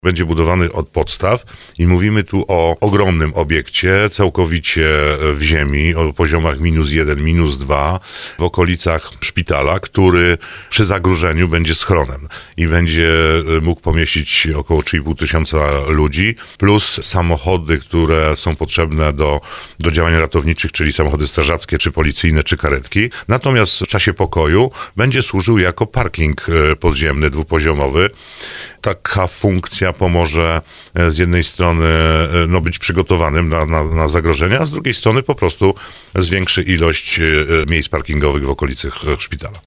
Starosta powiatu brzeskiego Andrzej Potępa przyznał w Słowo za Słowo, że starostwo złożyło już wniosek na opracowanie dokumentacji projektowej.